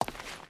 Stone Walk 1.ogg